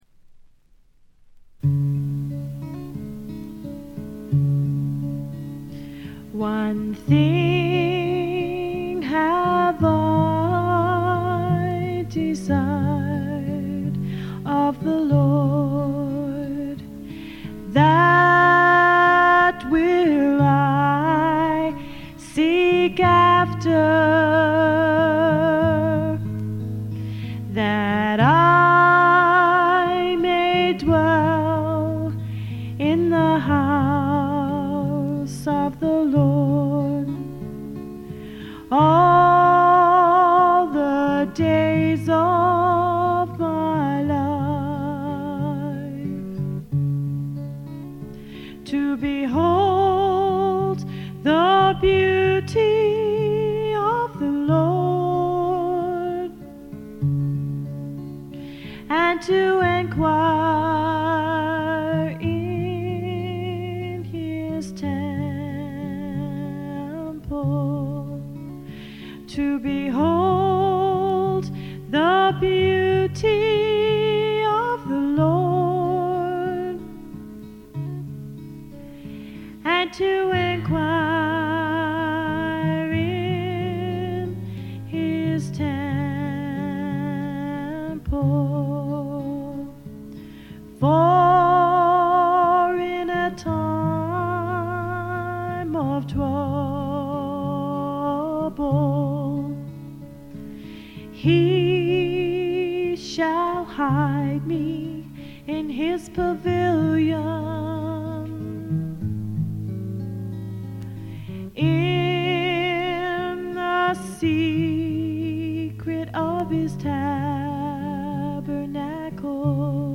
知られざるクリスチャン・フォーク自主制作盤の快作です。
試聴曲は現品からの取り込み音源です。